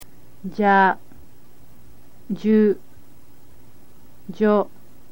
Pronounce them as JA, JU, and JO.
ja.mp3